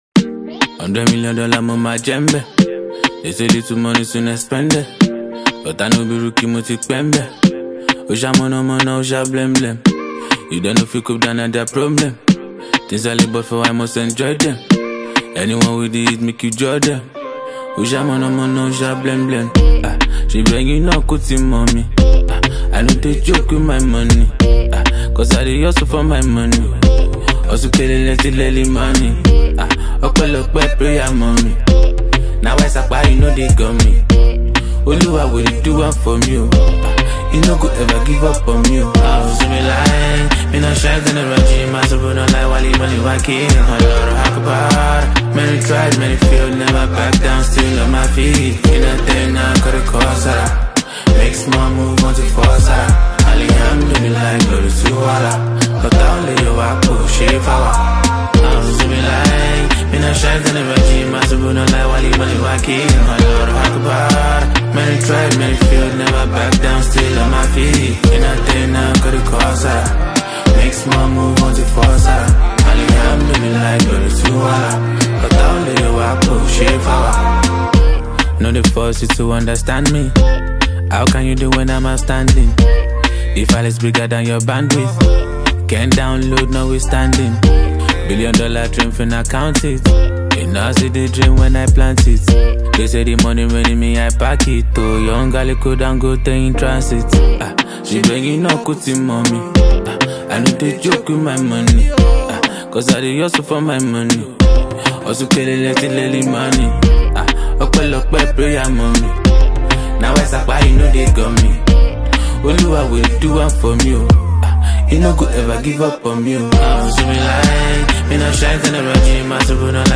Known for his lyrical depth and streetwise delivery
all laid over a haunting, melodic beat.
raw and emotional offering